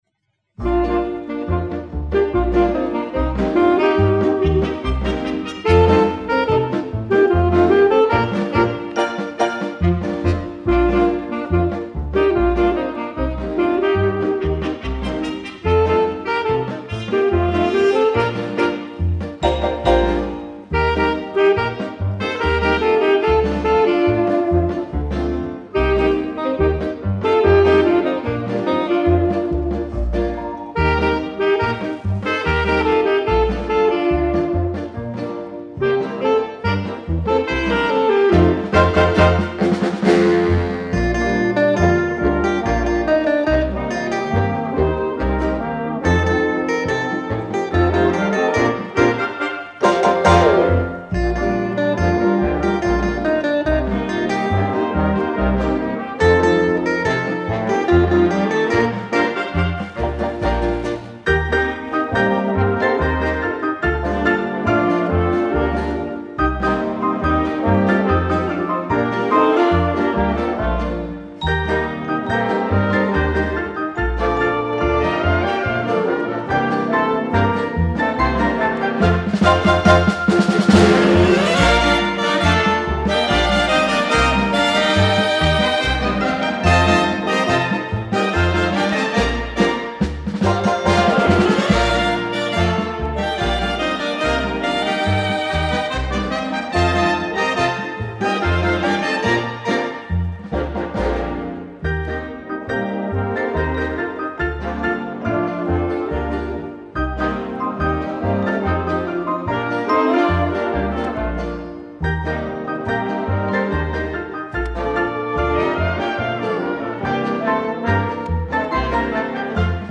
Первые четыре - в исполнении эстрадных оркестров.
Записан не с начала